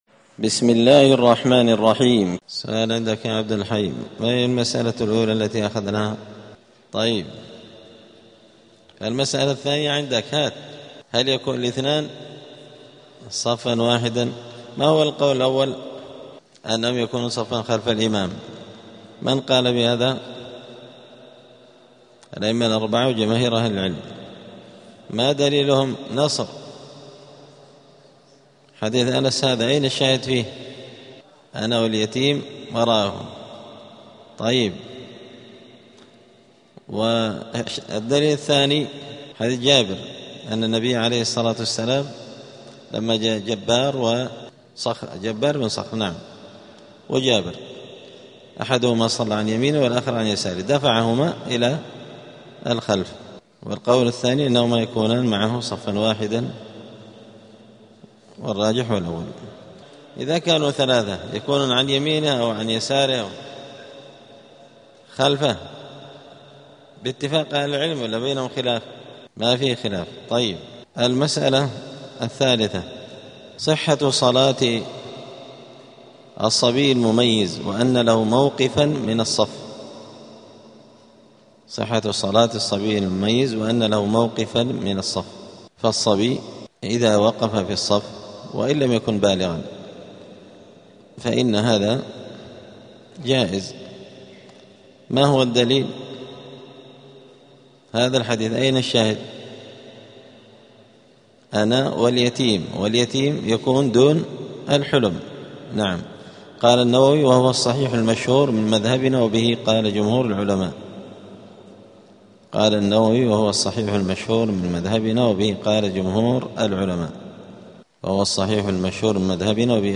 دار الحديث السلفية بمسجد الفرقان قشن المهرة اليمن
الدروس الأسبوعية